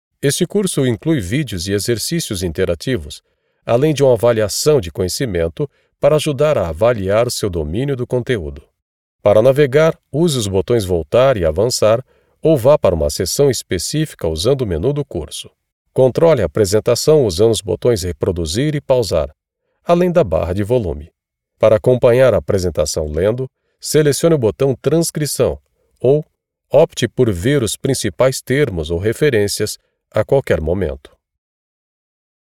Natuurlijk, Vriendelijk, Zakelijk, Commercieel, Veelzijdig
E-learning